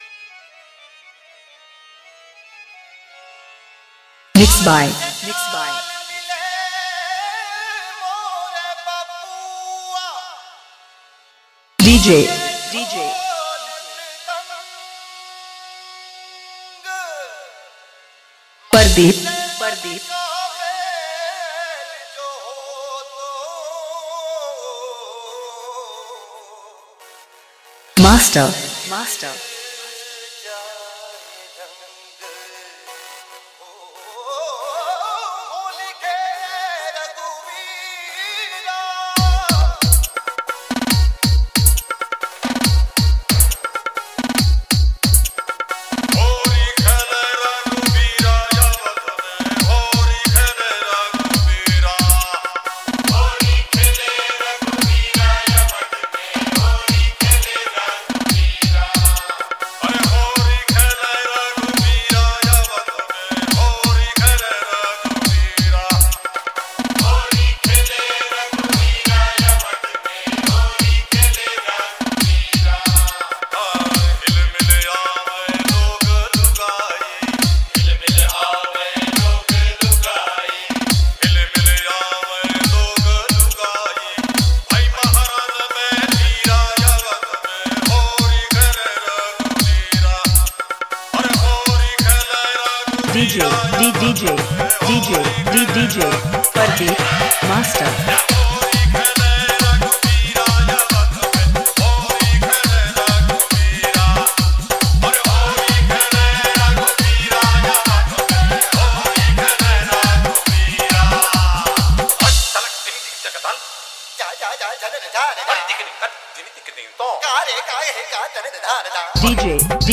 DJ Remix Songs